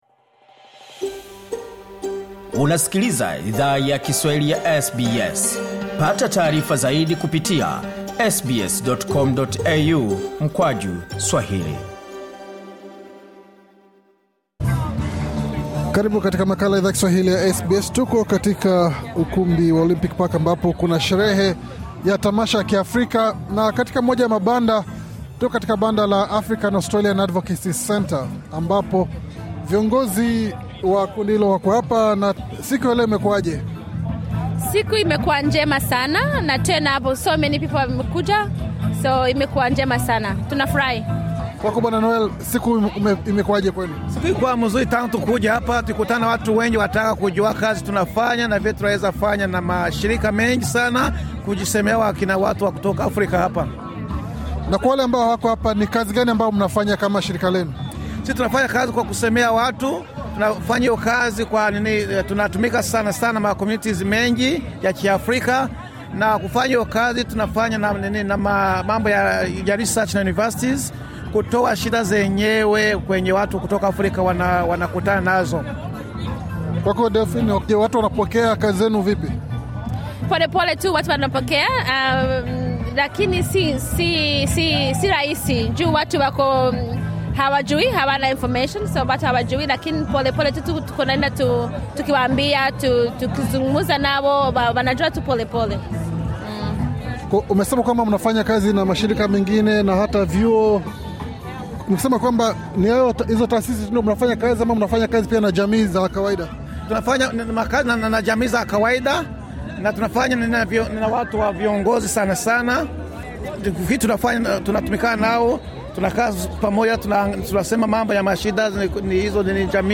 Katika mazungumzo maalum na SBS Swahili